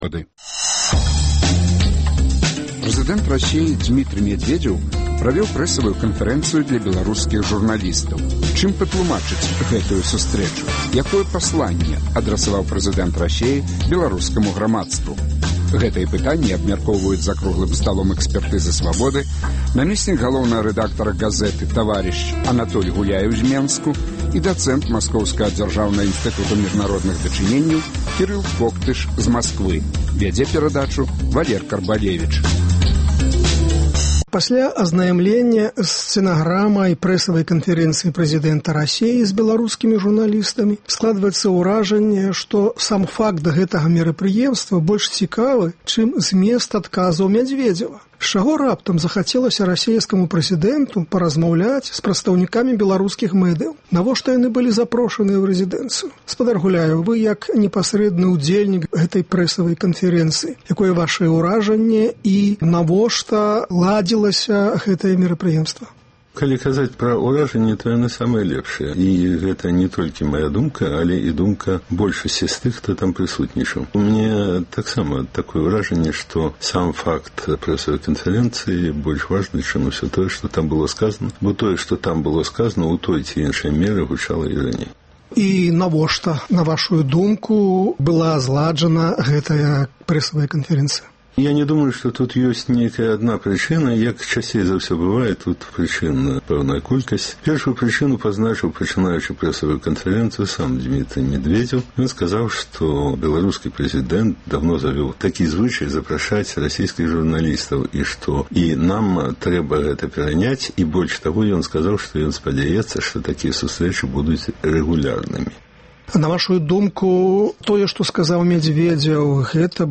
Якое пасланьне адрасаваў прэзыдэнт Расеі беларускаму грамадзтву? Гэтыя пытаньні абмяркоўваюць за круглым сталом